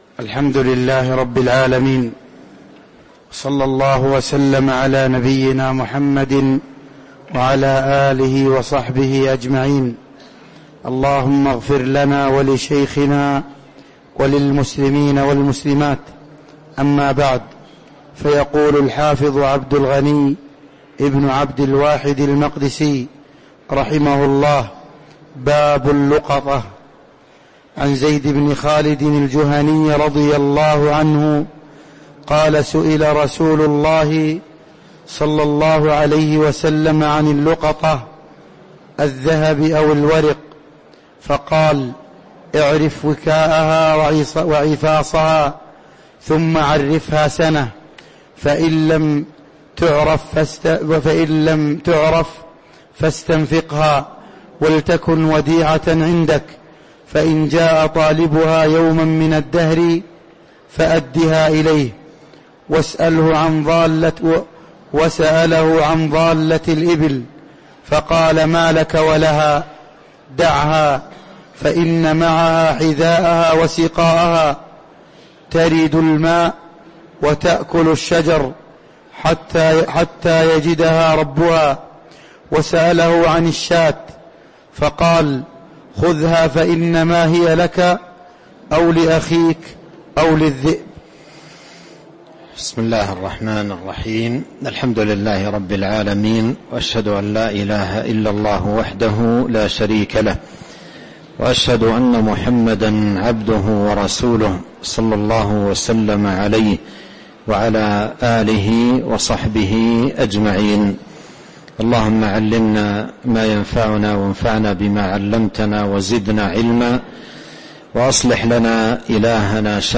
تاريخ النشر ١٠ رجب ١٤٤٤ هـ المكان: المسجد النبوي الشيخ